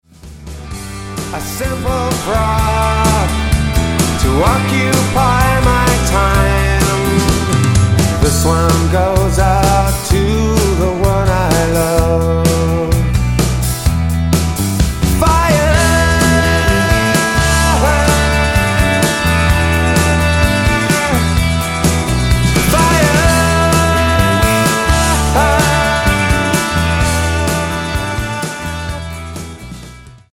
Tonart:Em Multifile (kein Sofortdownload.
Die besten Playbacks Instrumentals und Karaoke Versionen .